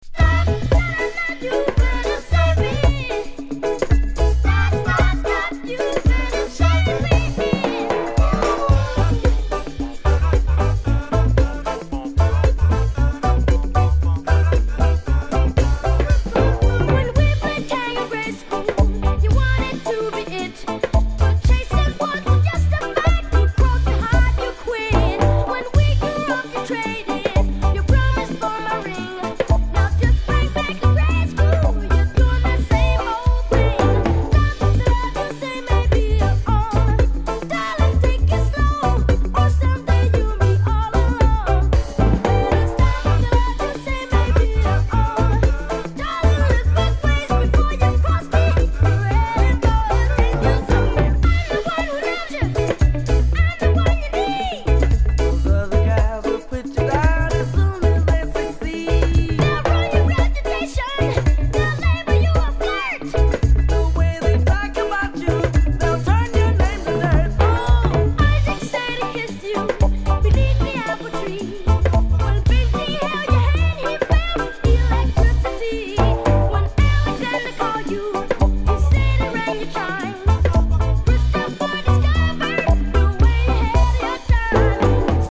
roots reggae band
rock steady to early reggae-flavored backing tracks
a cappella